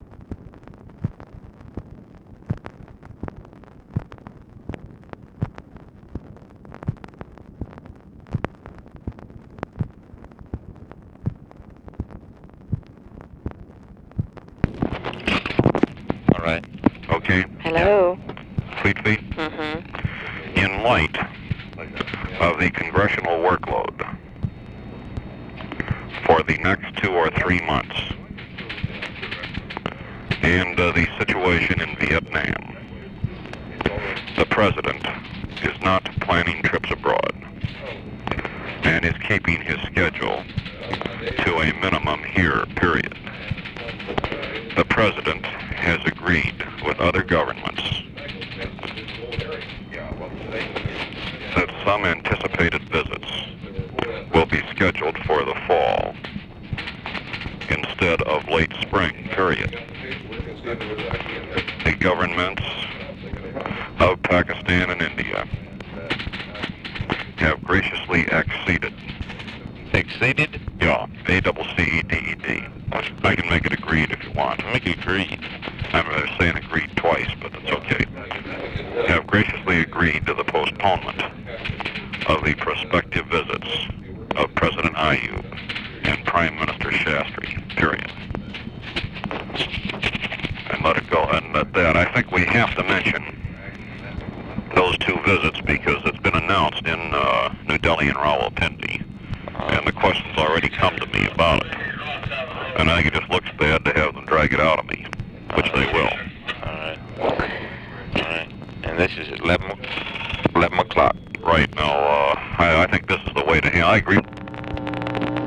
Conversation with OFFICE SECRETARY
Secret White House Tapes